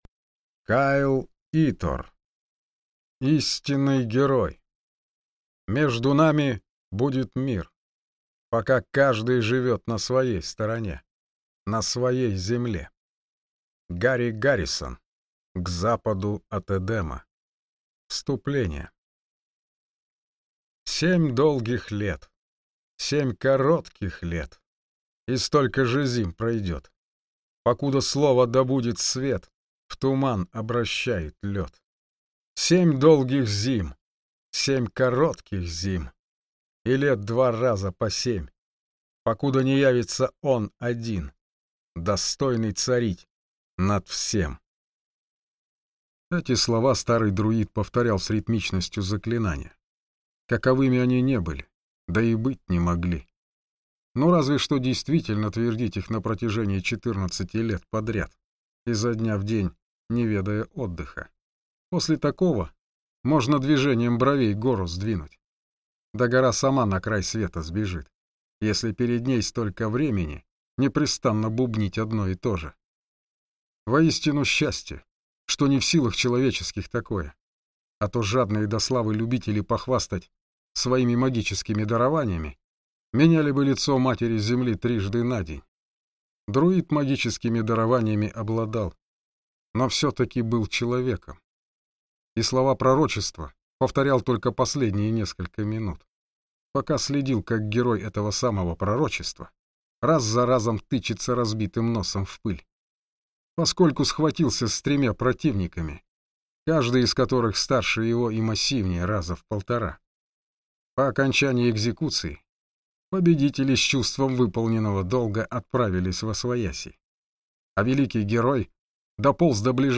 Аудиокнига Истинный герой | Библиотека аудиокниг